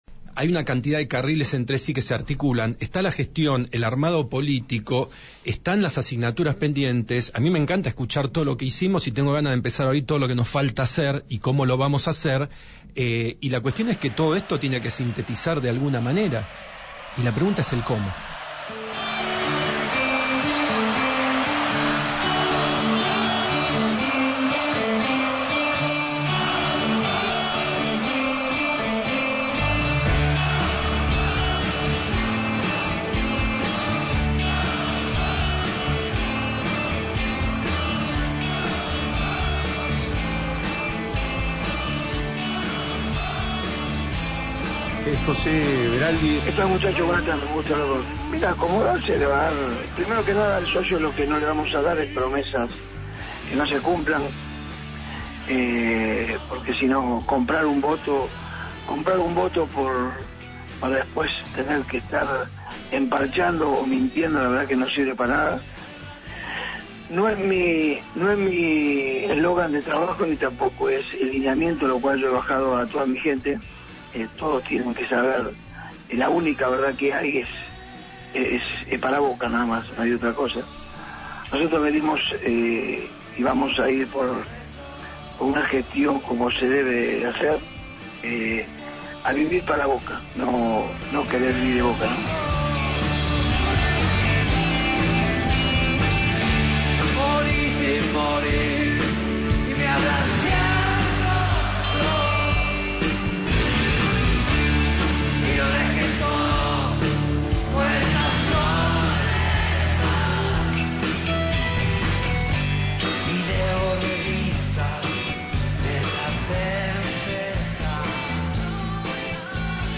Entrevistados en Buscando al General Duval, ambos dirigentes mostraron una cara diferente a la que encarna la actual conducción xeneize: el camino hacia un Boca Juniors social.